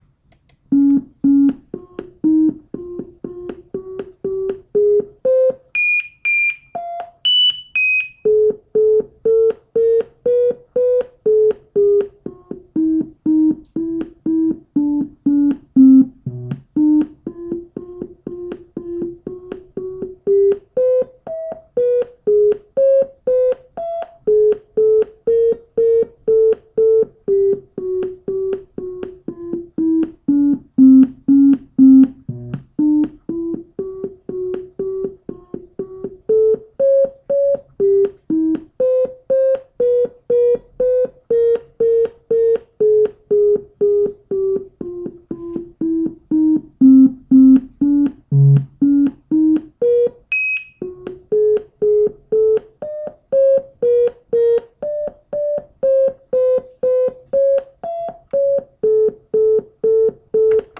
As the image constitutes few spaced out bright stars surround by dust and gas cloud of relatively uniform and lower luminosity, the music generated by the image is relatively softer and calmer with few higher notes.